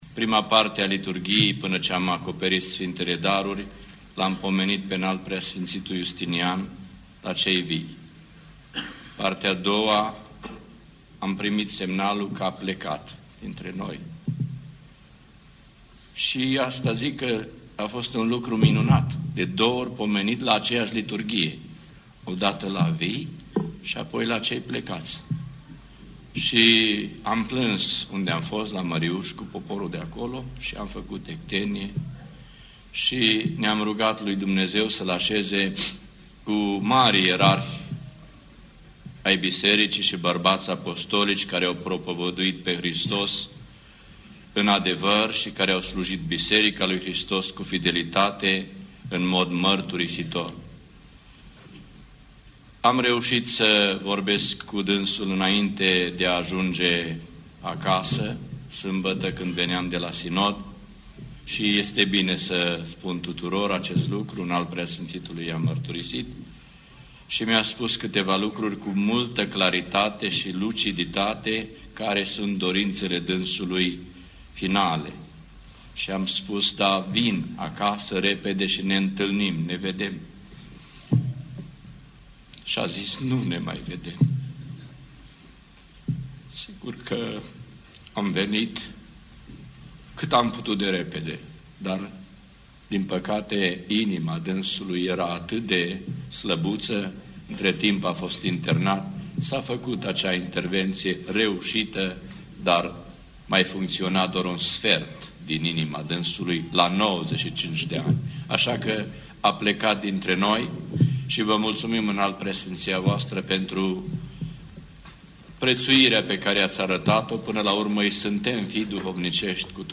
Maşina cu trupul neînsufleţit al Arhiepiscopului Justinian a ajuns la Catedrala Episcopală „Sfânta Treime” din Baia Mare, unde o mare mulţime de credincioşi din toată Episcopia îl aşteptau, atât în afara Catedralei, cât şi înăuntru.
Acum, l-am aşezat aici spre cinstire şi priveghere, a spus PS Iustin Sigheteanul.